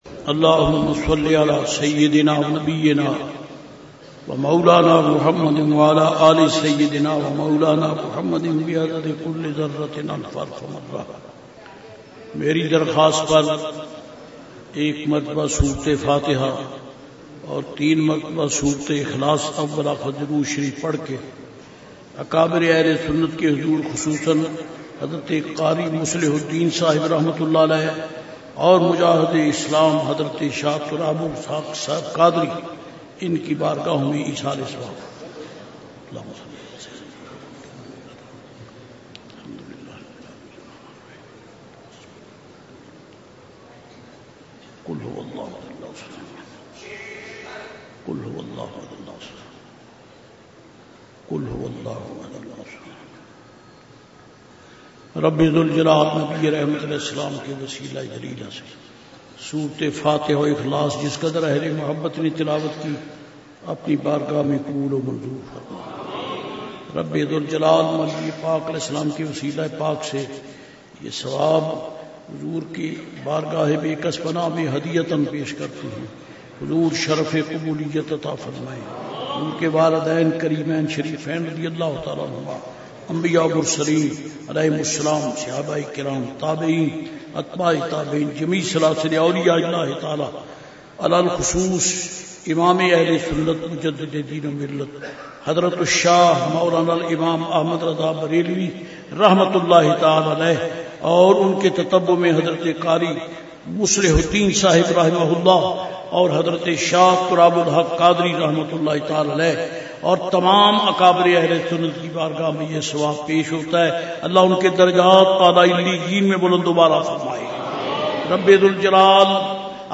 Dua 98 Urs-e-Alahazrat
dua-98-urs-e-alahazrat.mp3